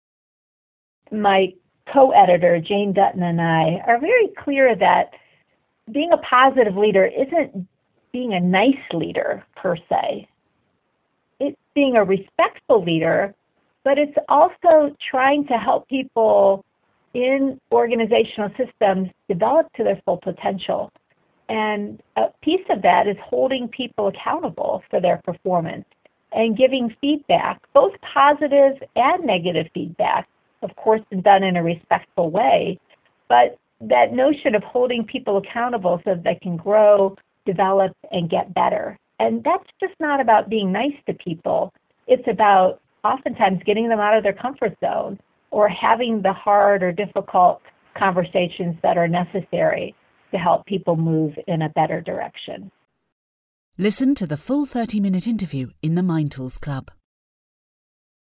In this audio clip, taken from our Expert Interview, she expands upon these ideas.